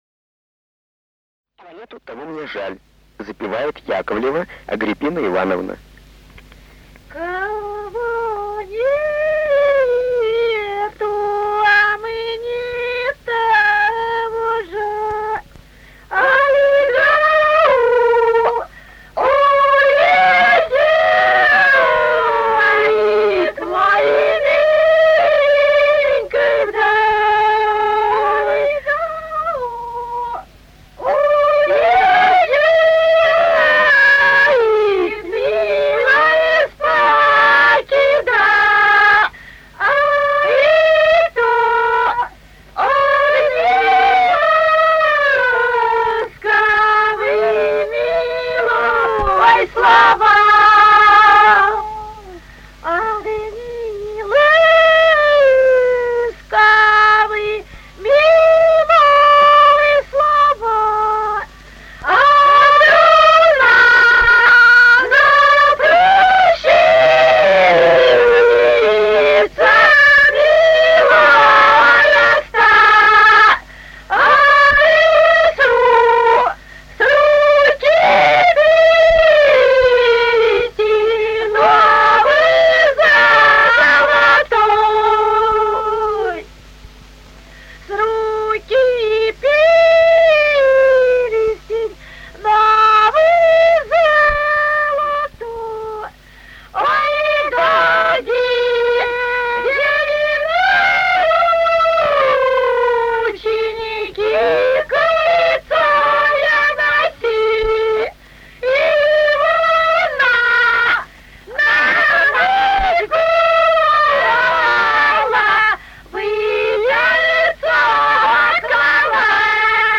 Русские народные песни Владимирской области [[Описание файла::18. Кого нету, а мне того жаль (лирическая) с. Мошок Судогодского района Владимирской области.
(запев)